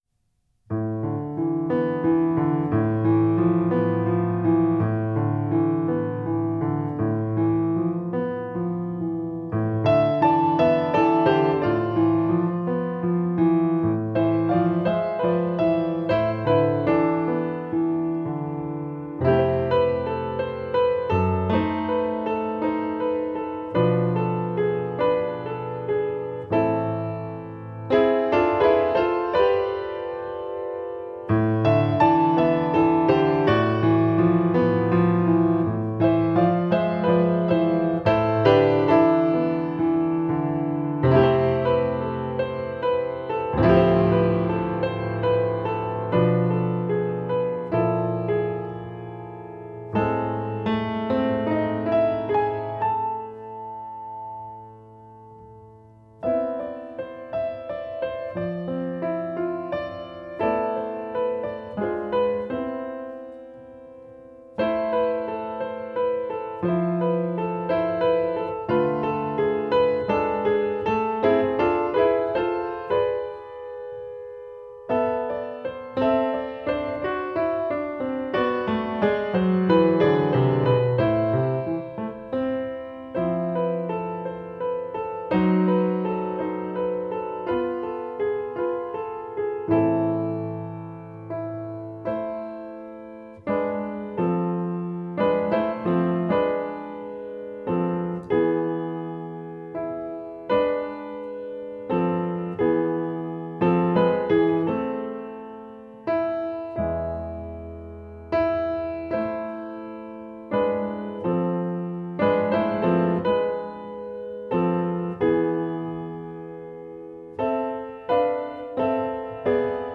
jazz pianist